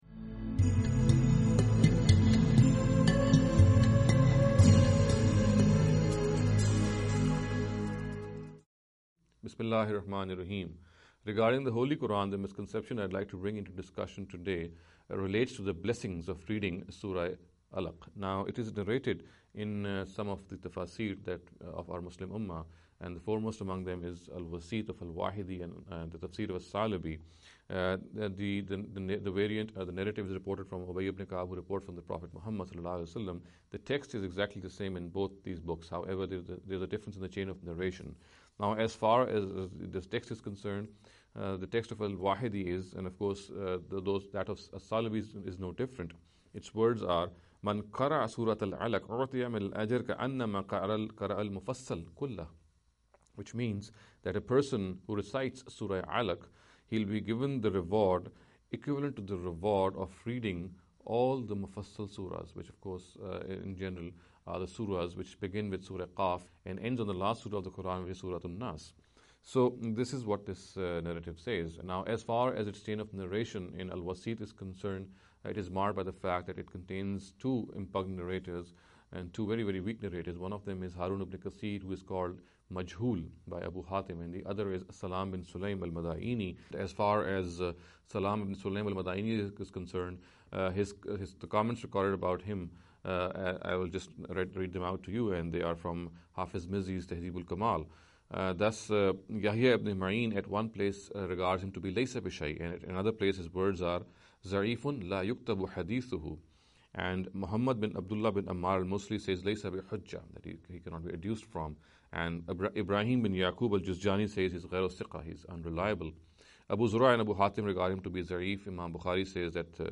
This lecture series will deal with some misconception regarding the Holy Quran. In every lecture he will be dealing with a question in a short and very concise manner.